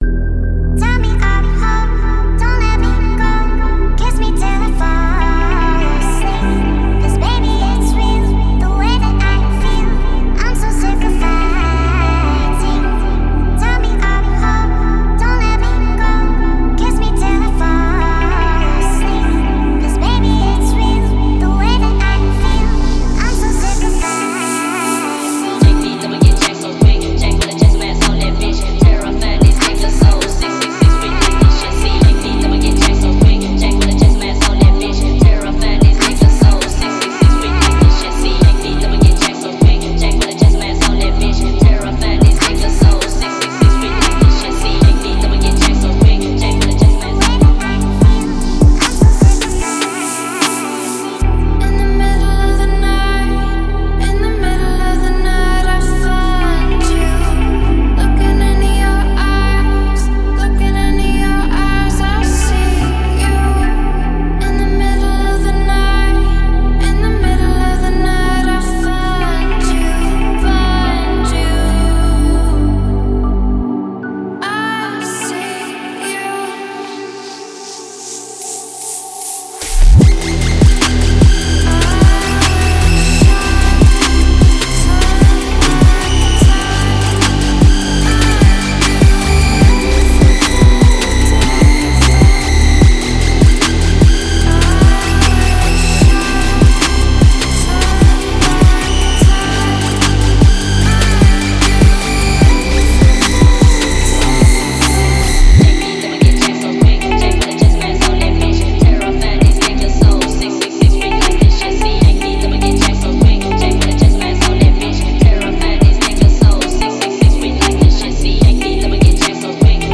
Фонк музыка
спокойный фонк
Красивый фонк